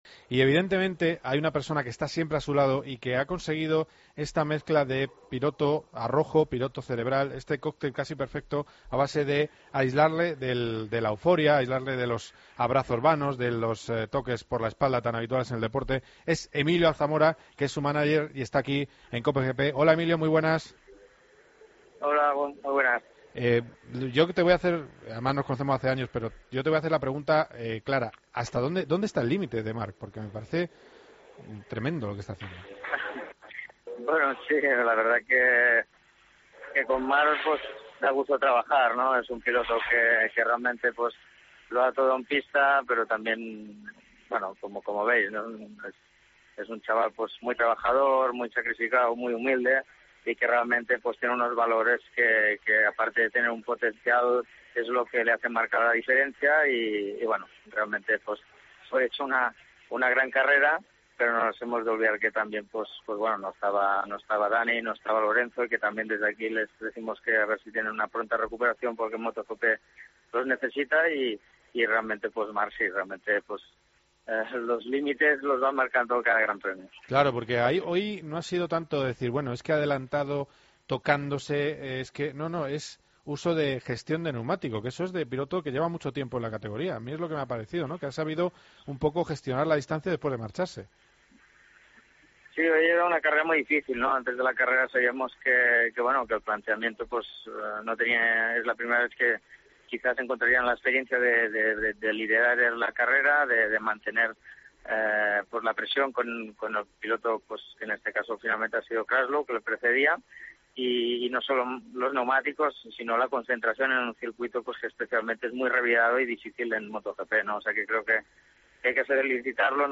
Entrevistamos al mánager de Marc Márquez.